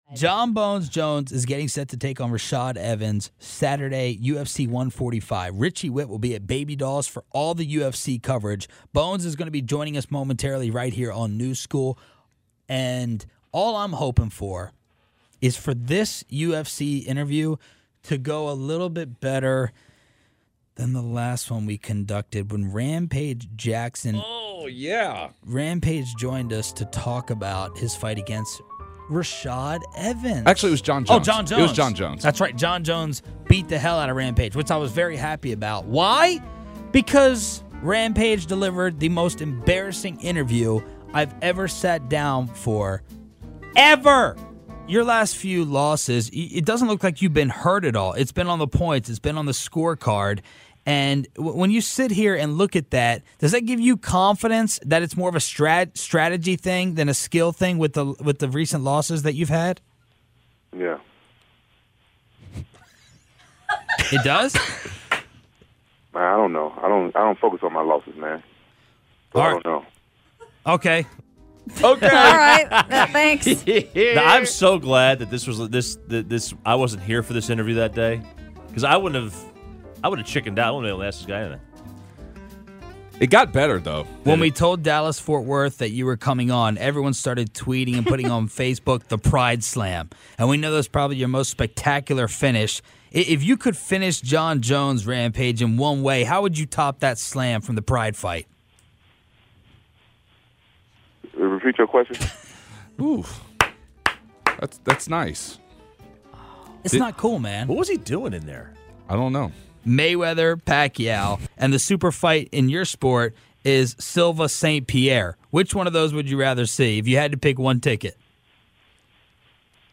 UFC Champion Jon 'Bones' Jones talks with New School to give a preview of the big UFC 145 fight night this Saturday.